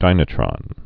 (dīnə-trŏn)